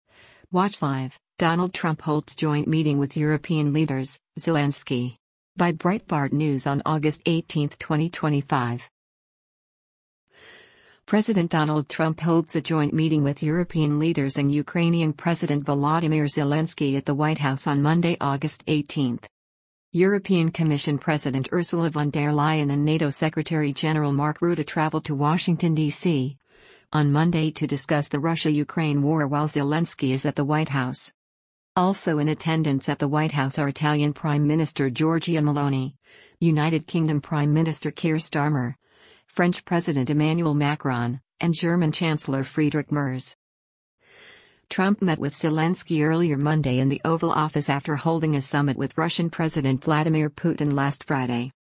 President Donald Trump holds a joint meeting with European leaders and Ukrainian President Volodymyr Zelensky at the White House on Monday, August 18.